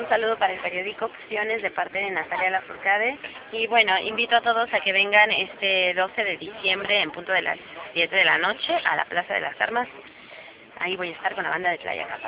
Saludo e Invitación de Natalia Lafourcade
Saludo-Natalia-Invitacion.wav